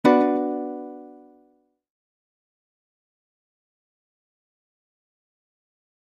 Music Effect; Electric Piano Chord, With Slap Back Echo.